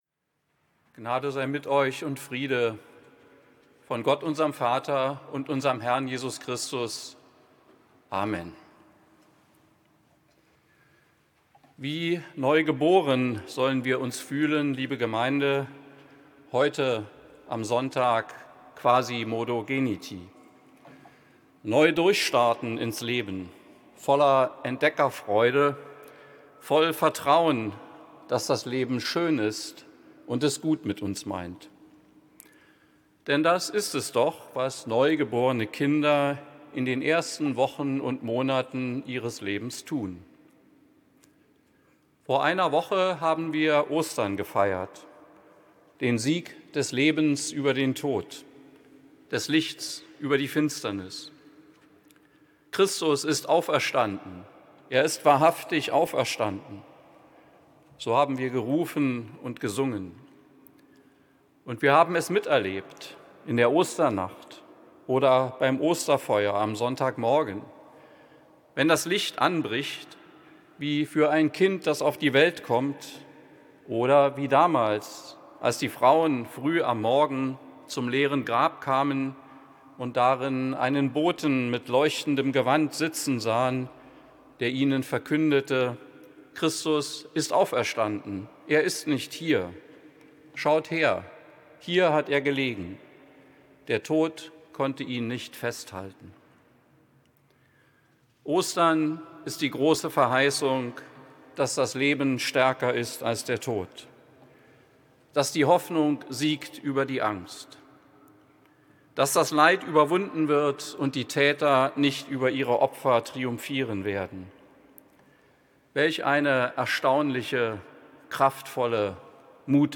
Predigten 2023